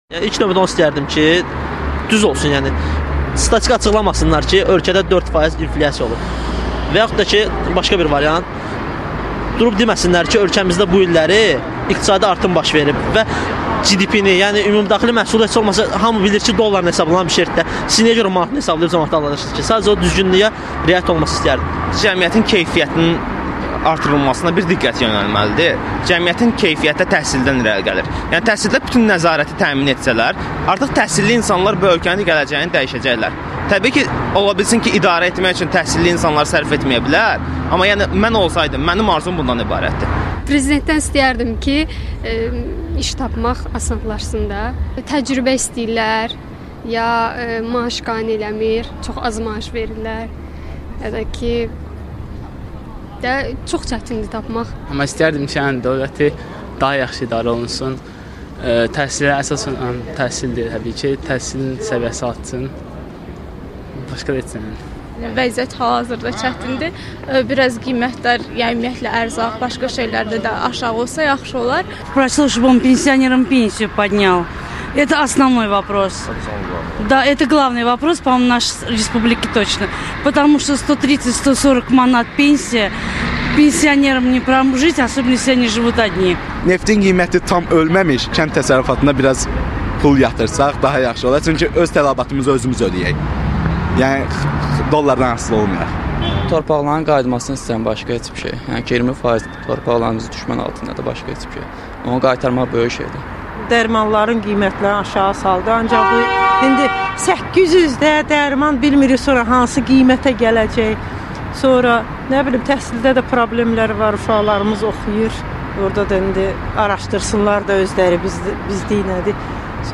İmkanınız olsaydı, Azərbaycan prezidenti İlham Əliyevdən nəyi soruşardınız? - Bakıda sorğu
Sorğu: Prezidentdən nəyi soruşardınız?